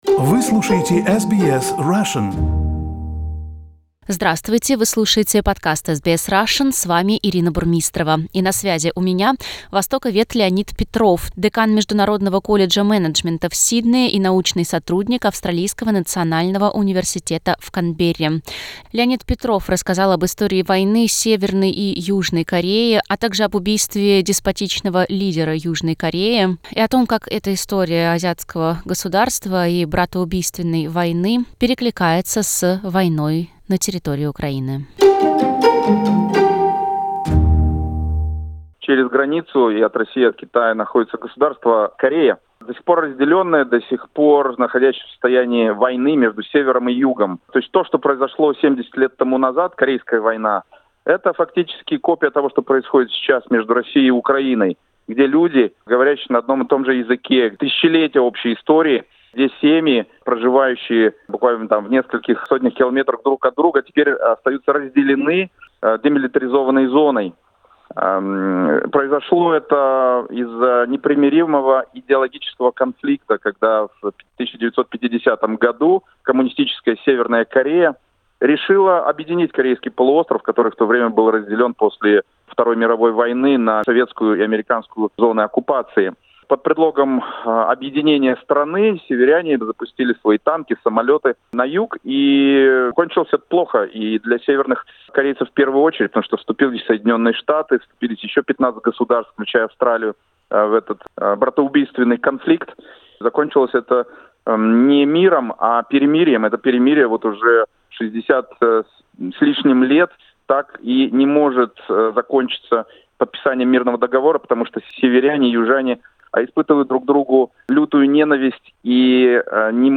Комментарий